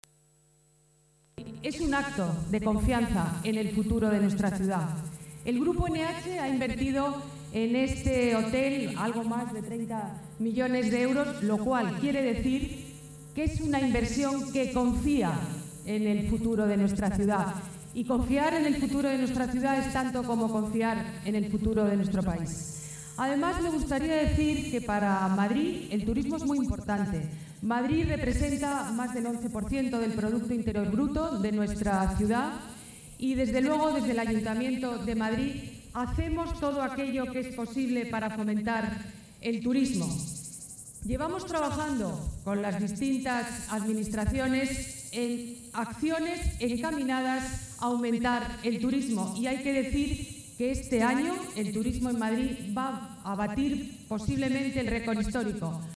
La alcaldesa asiste a la reapertura de este establecimiento con cuatro décadas de existencia, tras las obras de remodelación llevadas a cabo
Nueva ventana:Declaraciones de la alcaldesa de Madrid, Ana Botella: Hotel Eurobuilding